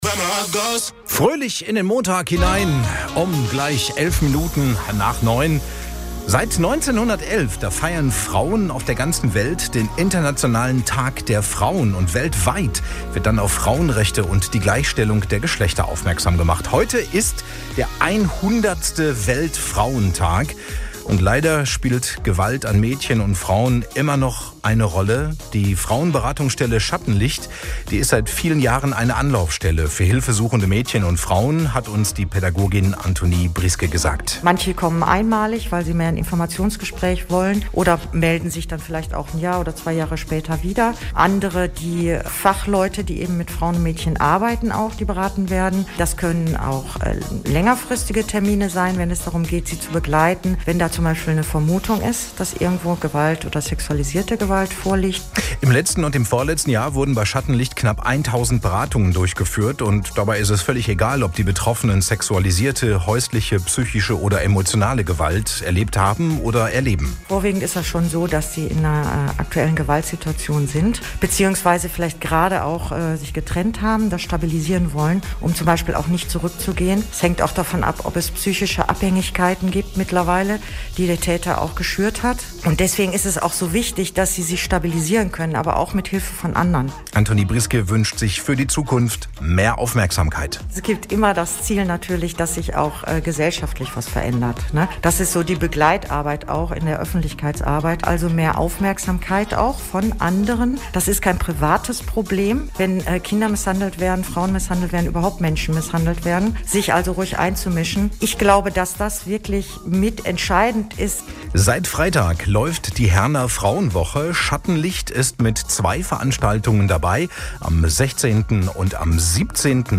Radio Herne_08.3.21_110 Jahre Internationaler Weltfrauentag – Schattenlicht e.V. im Radio Herne Interview
Frauentag_2021-_Radiointerviewmitschnitt_schattenlicht.mp3